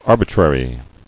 [ar·bi·trar·y]